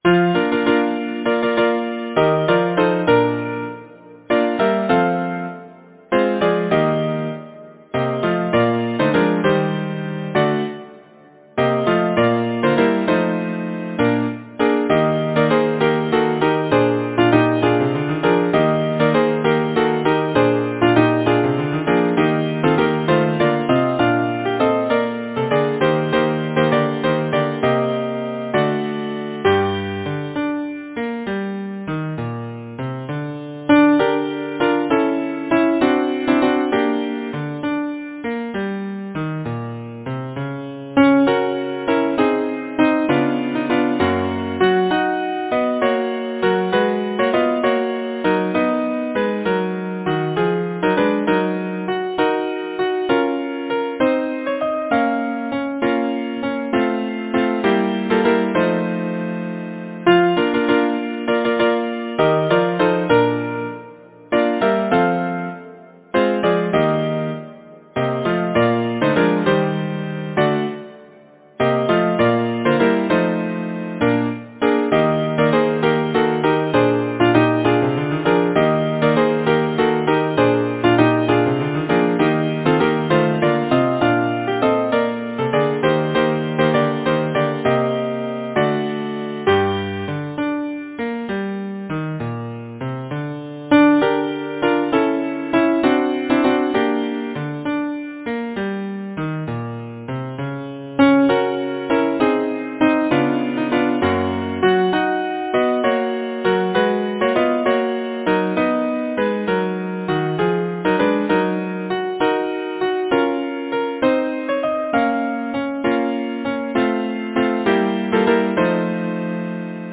Number of voices: 4vv Voicing: SATB Genre: Secular, Partsong
Language: English Instruments: Keyboard